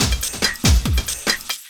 04 LOOP01 -L.wav